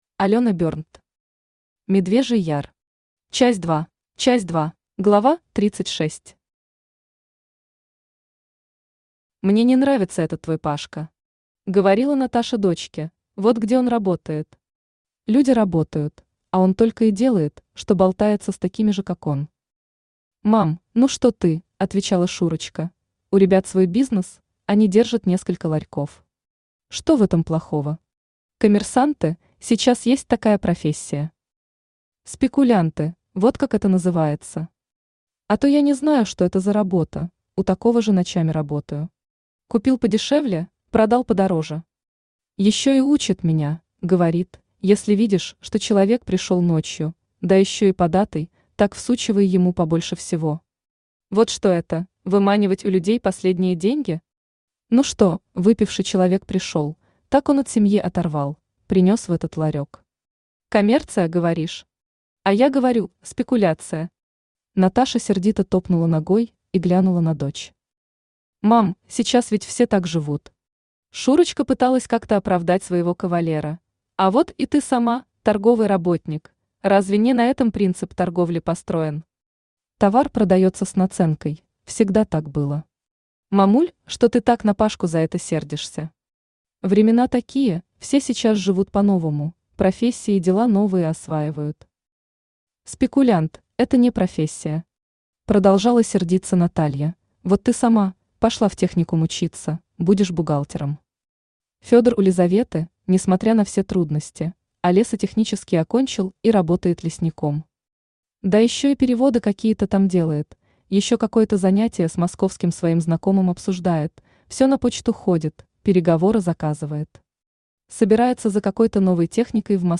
Аудиокнига Медвежий Яр. Часть 2 | Библиотека аудиокниг
Часть 2 Автор Алёна Берндт Читает аудиокнигу Авточтец ЛитРес.